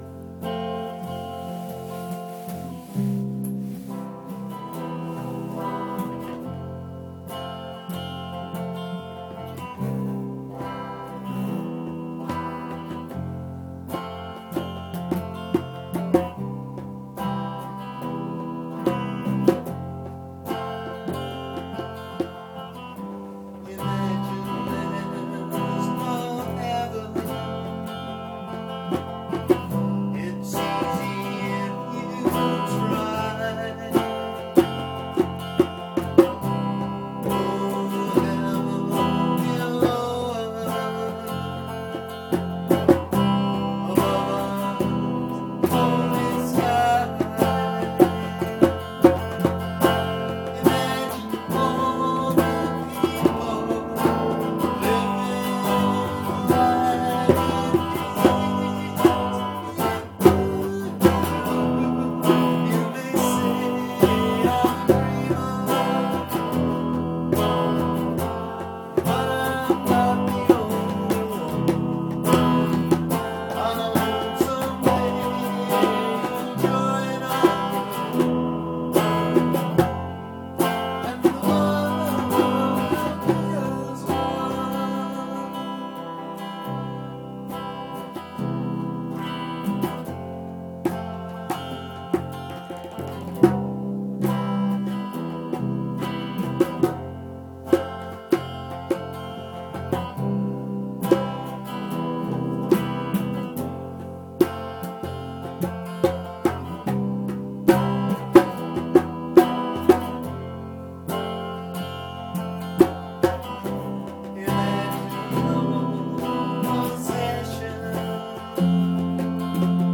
Just in case anyone was wondering what kind of noises were made at the Famous ‘Burnside Refugees’ Jam Session, I’ve decided to post a couple of examples of some of our best… First, here’s John Lennon’s “Imagine”: